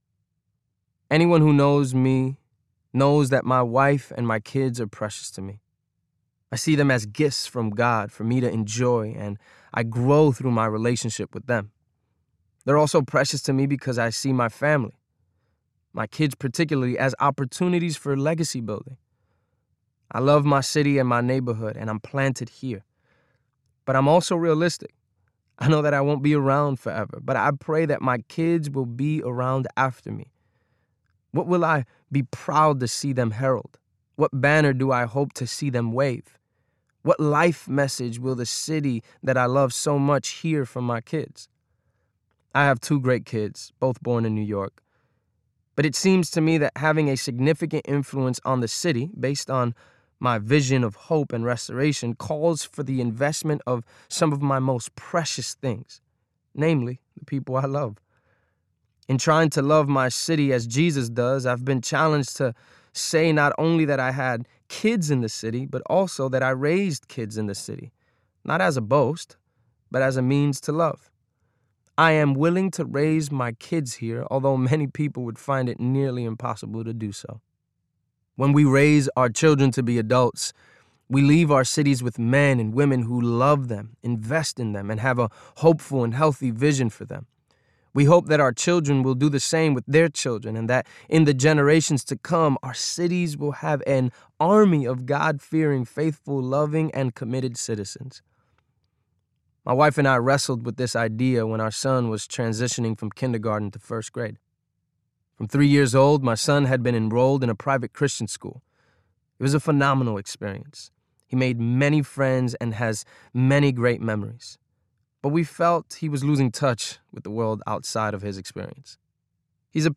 Mi Casa Uptown Audiobook
Narrator